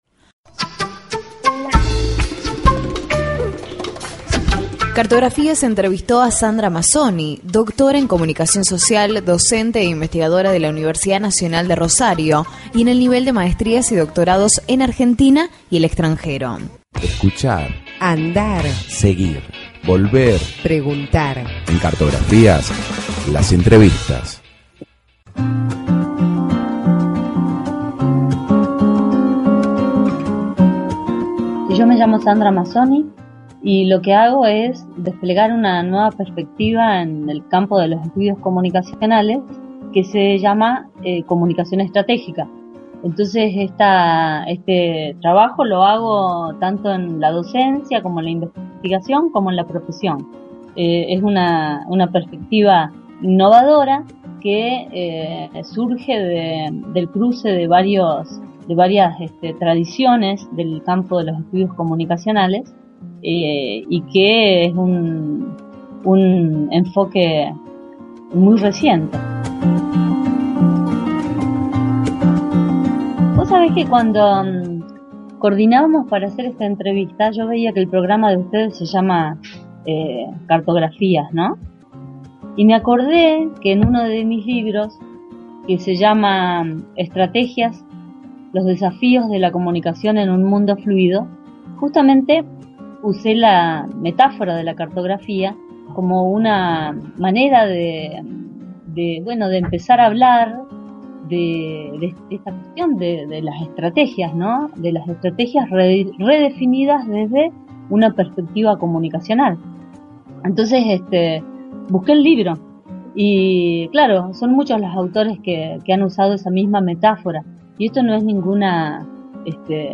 Comparto un programa en la radio de la Universidad Nacional de San Luis en el que participo como entrevistada sobre comunicación estrategica en las Organizaciones No Gubernamentales. cartografías.mp3 (7.92 Mb)